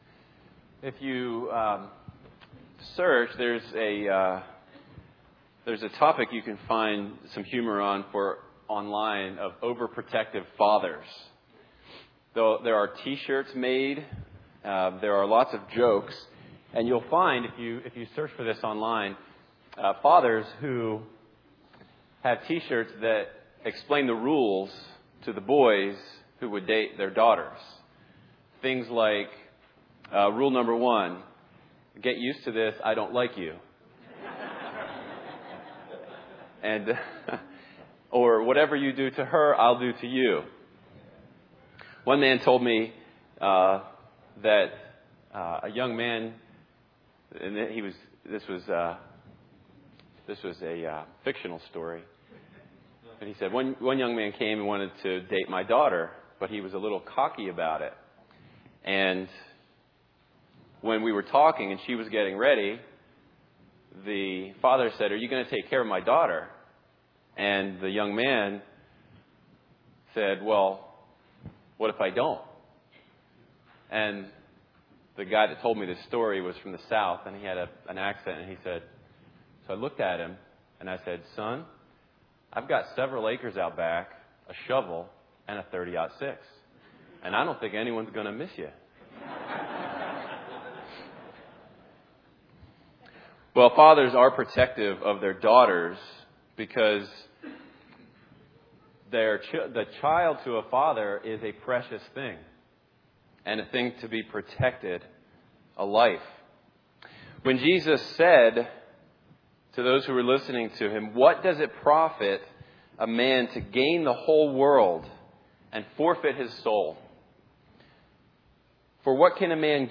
A Collection of 2016 Sermons